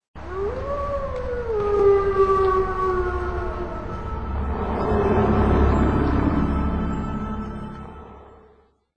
Whitewolf howl
whitewolf.wma